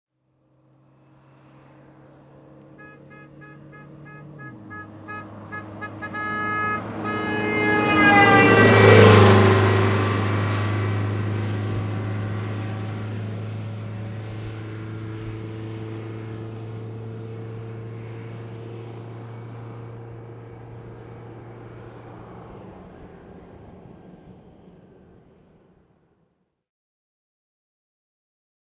Bus By With Doppler Horn Honks, Medium Distant